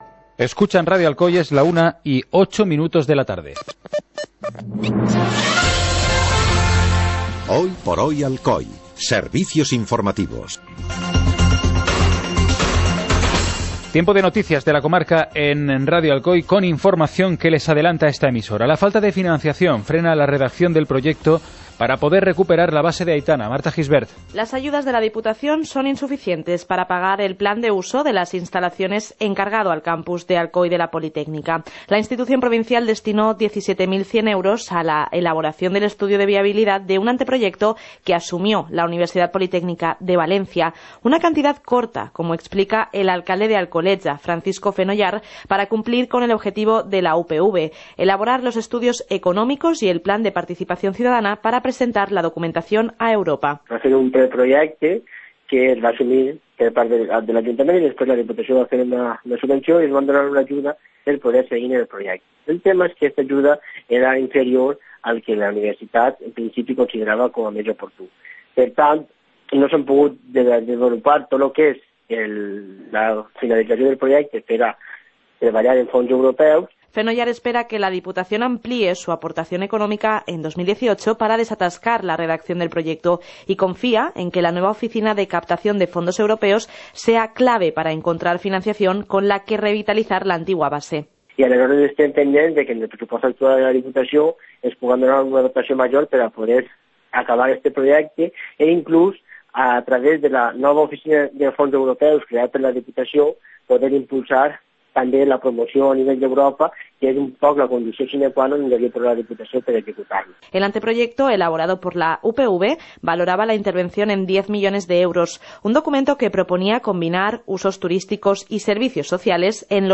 Informativo comarcal - jueves, 14 de diciembre de 2017